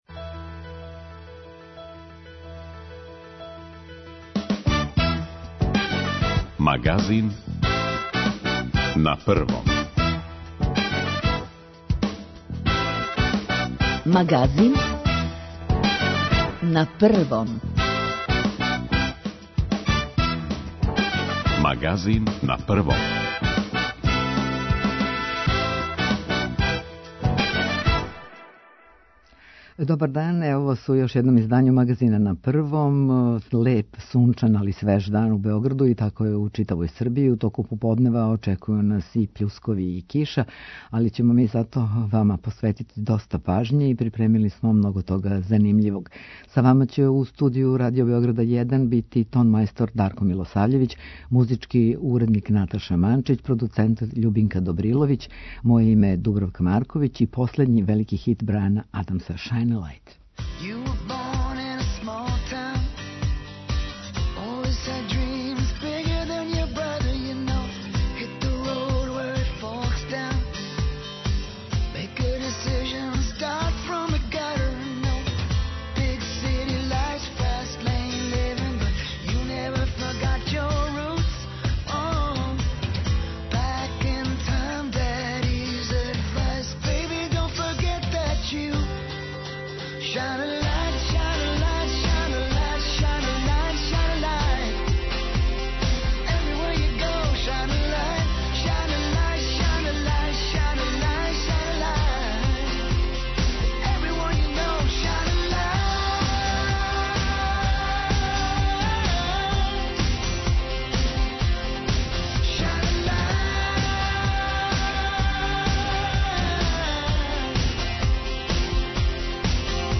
Доносимо најновије информације о епидемији која је захватила велики део света, тражимо савете стручњака о томе како се понашати у условима епидемије и ванредног стања, пратимо стање на терену, слушамо извештаје наших репортера из земље и света.